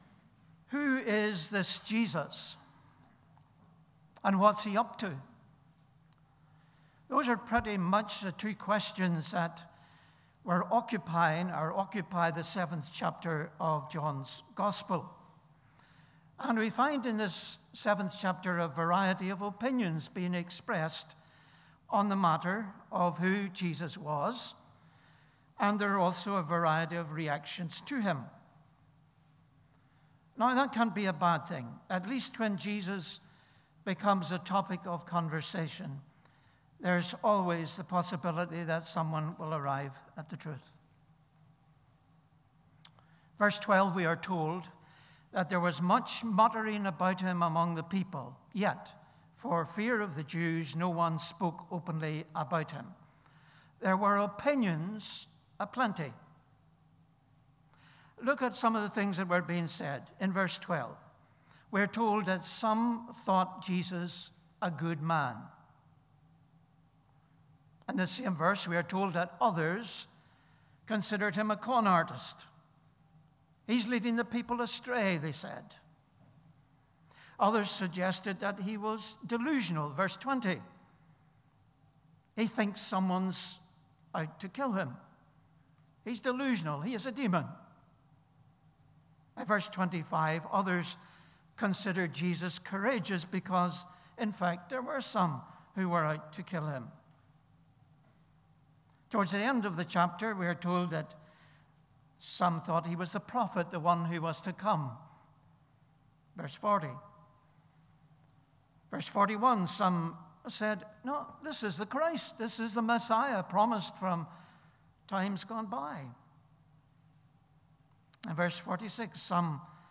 Listen to sermon audio. John 7 Jesus at the Feast of Booths 7:1 After this Jesus went about in Galilee.